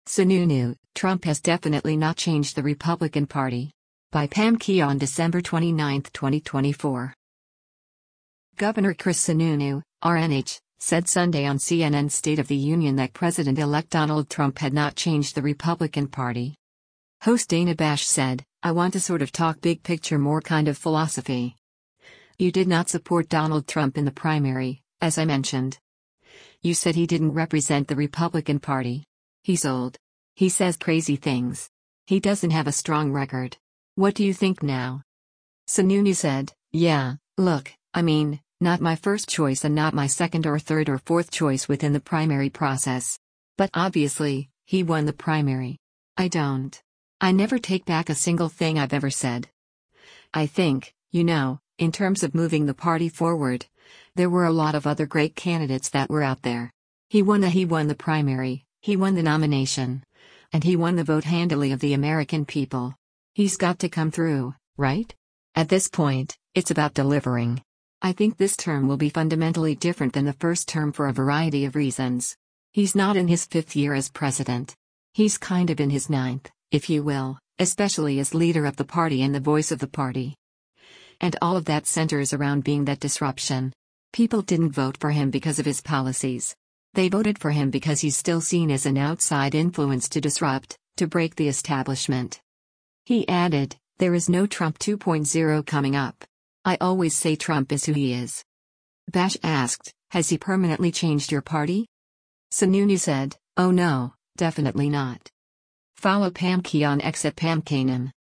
Governor Chris Sununu (R-NH) said Sunday on CNN’s “State of the Union” that President-elect Donald Trump had not changed the Republican Party.